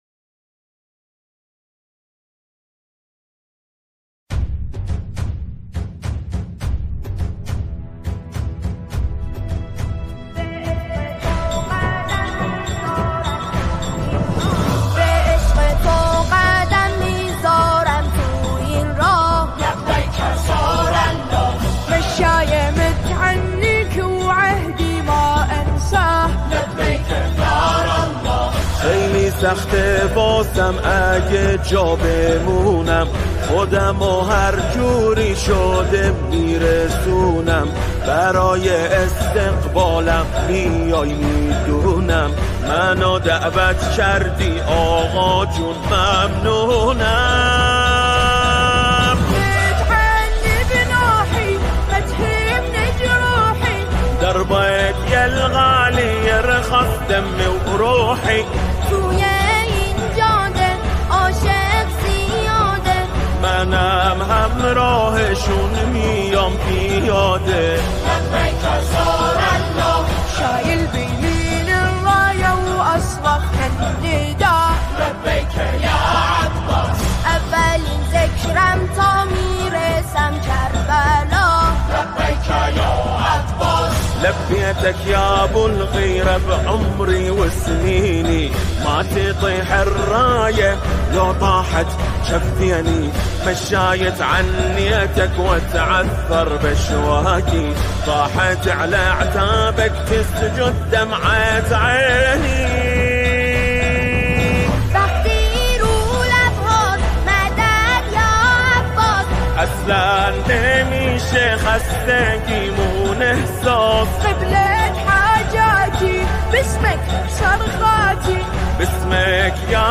دانلود نماهنگ دلنشین